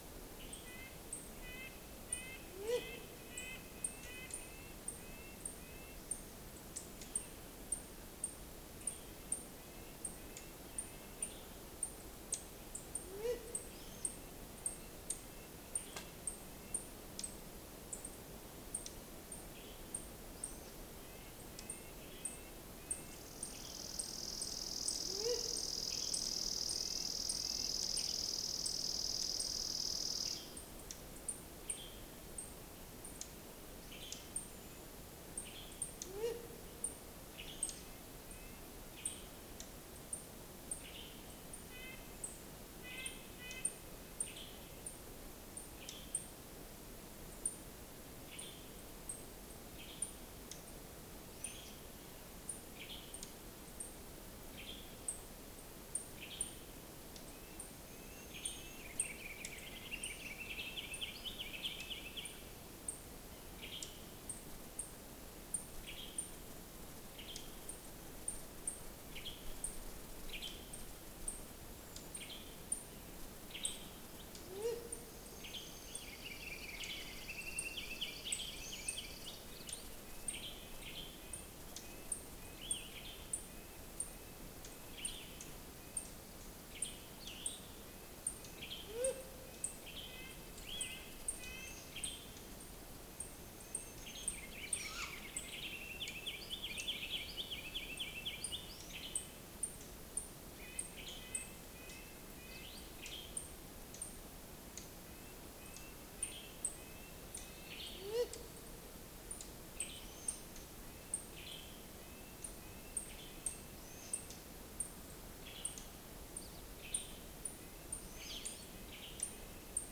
These 2-minute samples were recorded side by side in June in a quiet but very vocal Montana forest.  The audio is unedited, except for normalizing the amplitude to more accurately compare the two signals.
Owl Sense v1.1 MEMS Microphone